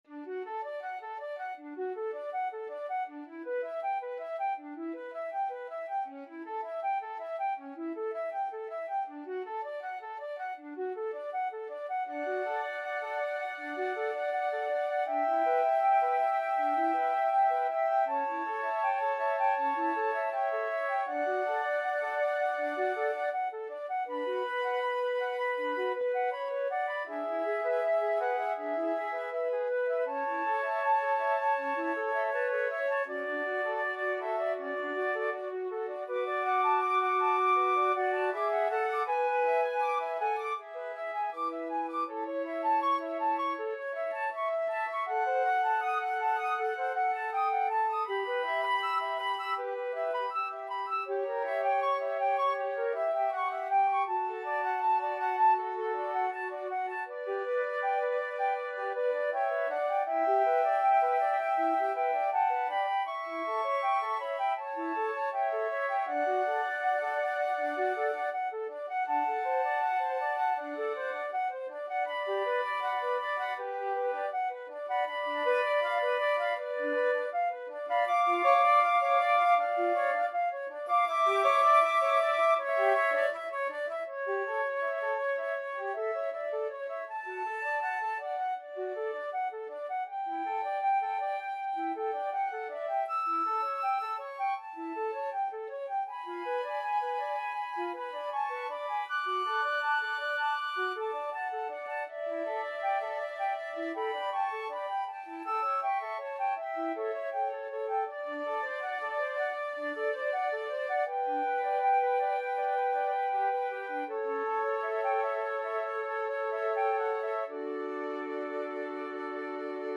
D major (Sounding Pitch) (View more D major Music for Flute Quartet )
4/4 (View more 4/4 Music)
Andante
Flute Quartet  (View more Easy Flute Quartet Music)
Classical (View more Classical Flute Quartet Music)
gounod_ave_maria_4FL.mp3